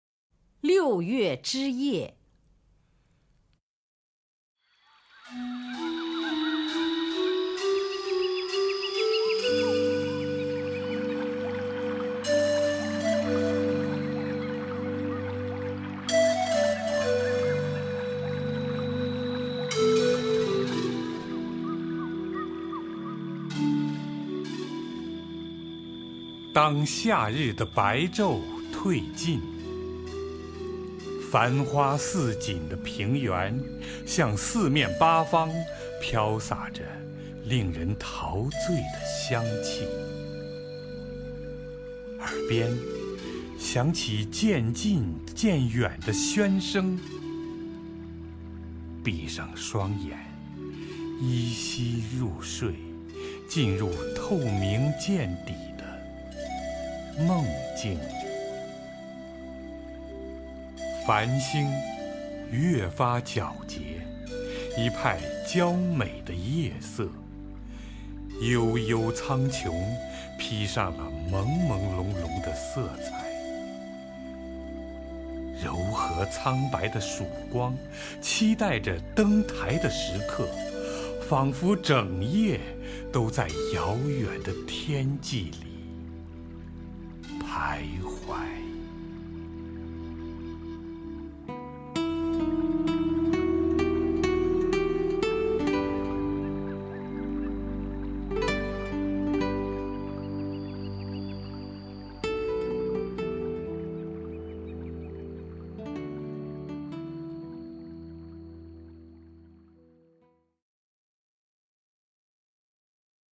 首页 视听 名家朗诵欣赏 乔榛
乔榛朗诵：《六月之夜》(（法）维克多-马里·雨果)